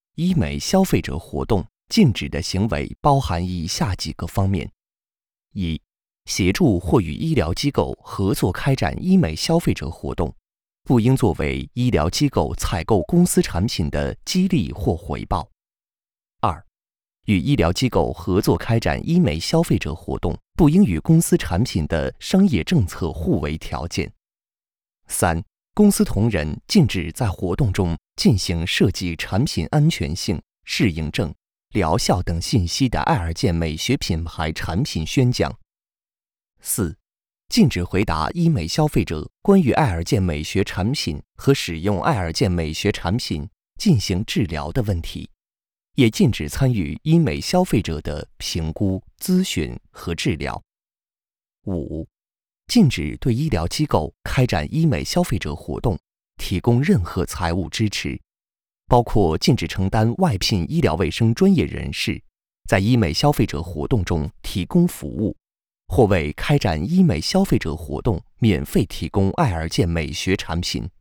Chinese_Male_005VoiceArtist_20Hours_High_Quality_Voice_Dataset
Text-to-Speech